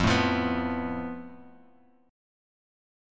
FMb5 chord